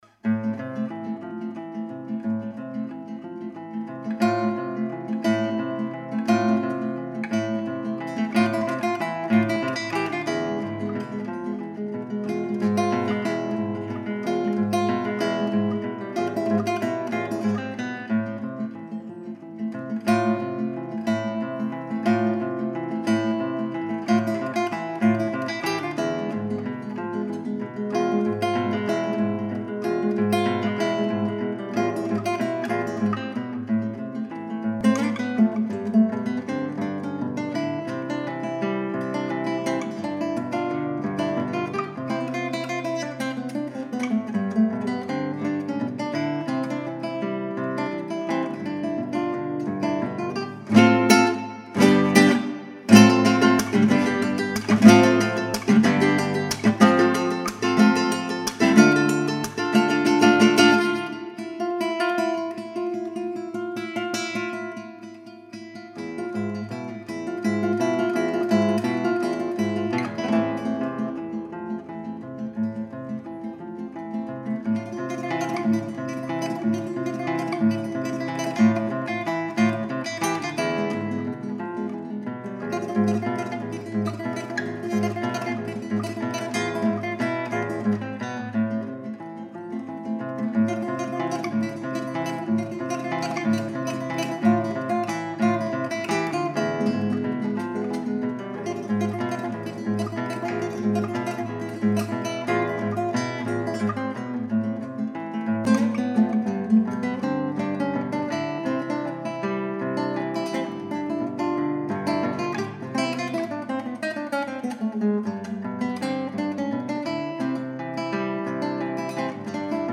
Ziemlich bald sattelte ich dann auf die Gitarre um.
Nebenan Heimatlied Bongiorno Herr Stein Dam dam di dam Und ein paar Klassik-Aufnahmen von mir kann man auch noch hören.